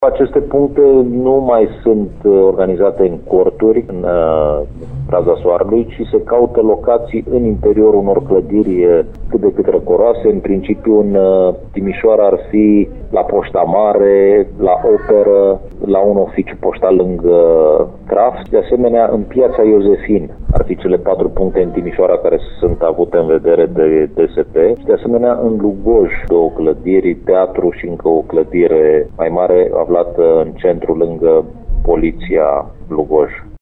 Toate spațiile vor fi dotate cu aer condiționat, apă și tensiometre, care sunt deservite de cadre medicale specializate, spune subprefectul Ovidiu Drăgănescu.